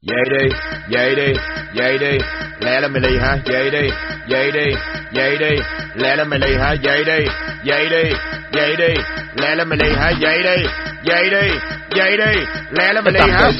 Nhạc Chuông Báo Thức